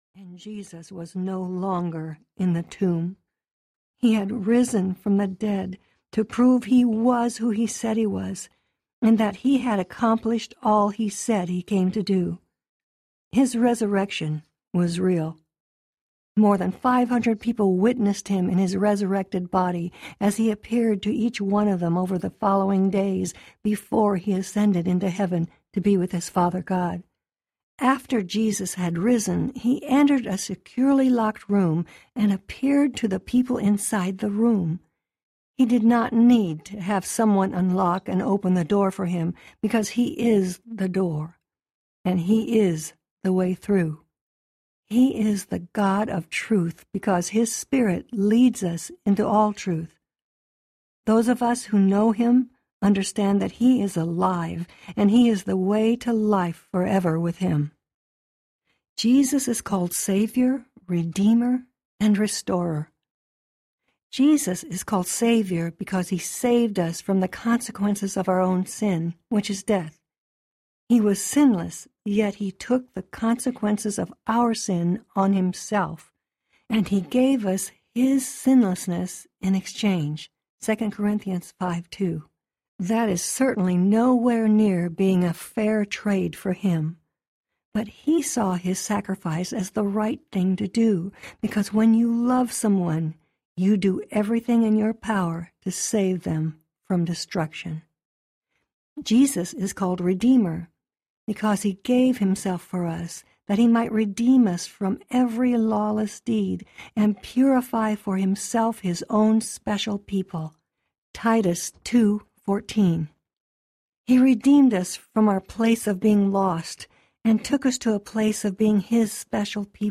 Choose Love Audiobook